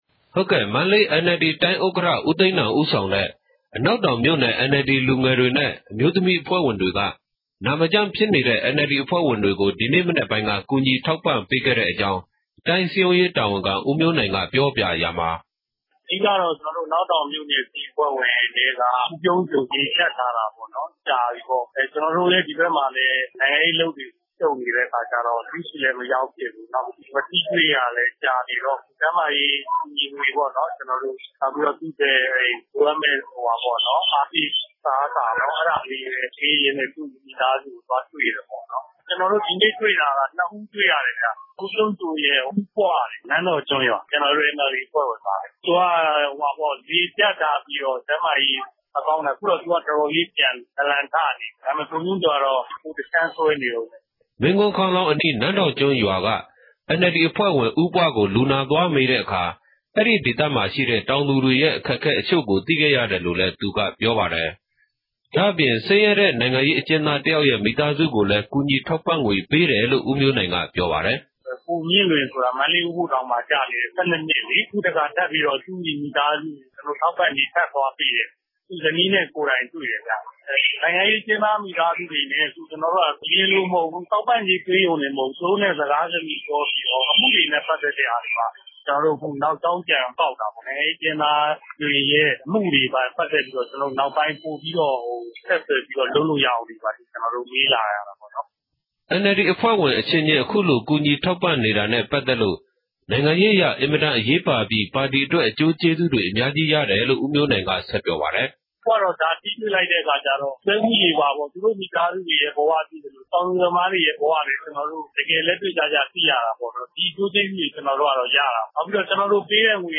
သတင်းပေးပို့ချက်။